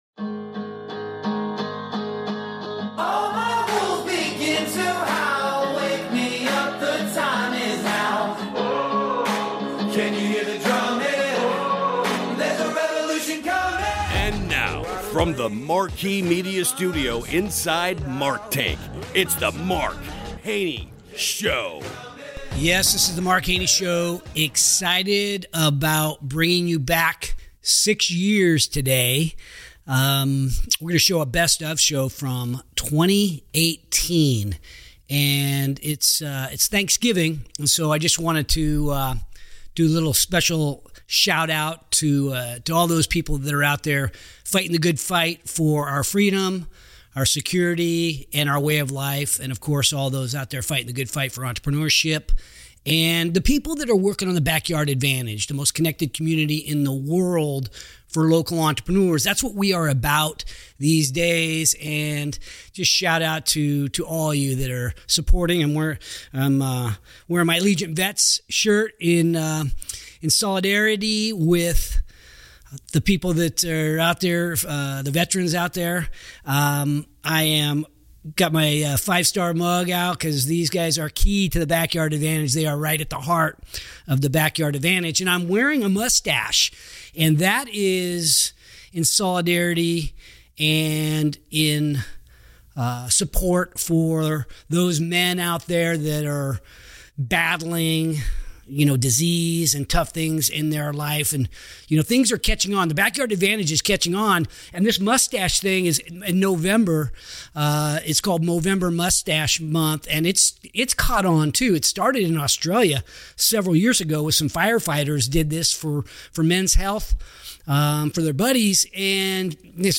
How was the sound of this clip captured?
Recorded during Thanksgiving week in 2018, this heartfelt conversation reflects on the...